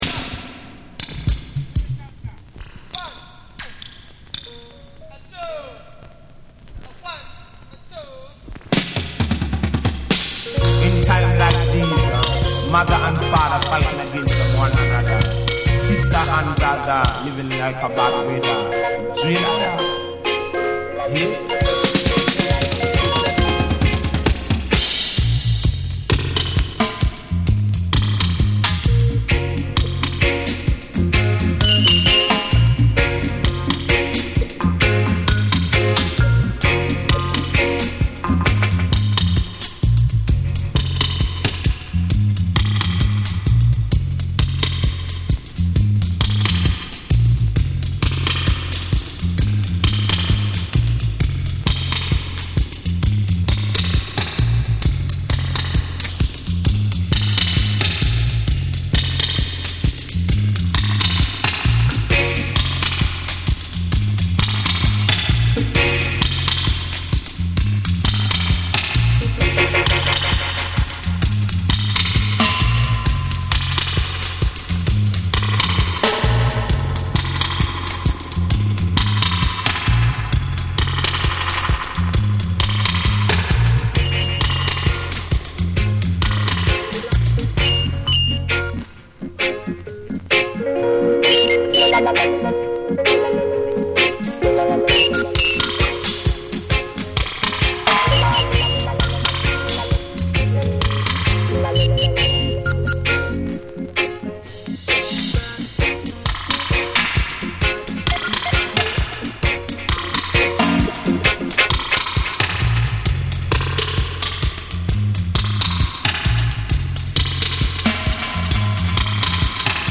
ヘヴィーなベースラインと鋭く切り込むエフェクトワークが際立つ1枚。
REGGAE & DUB